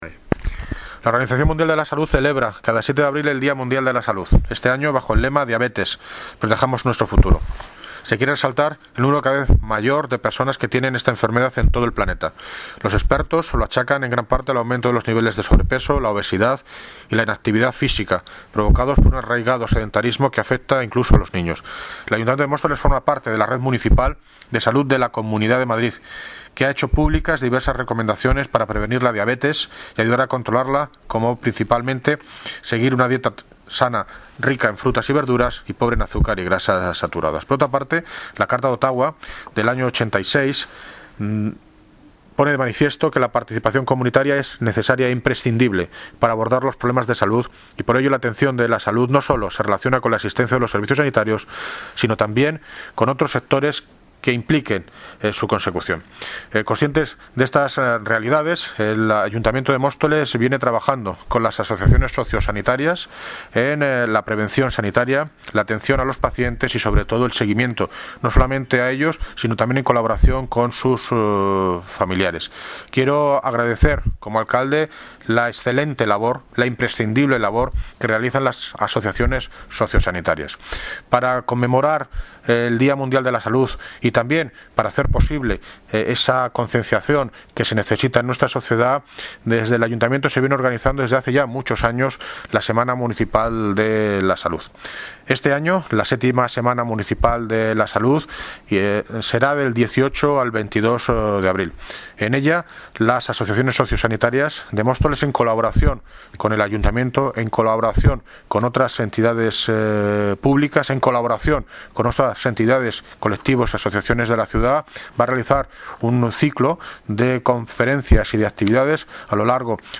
Audio - David Lucas (Alcalde de Móstoles) Sobre DIA MUNDIAL DE LA SALUD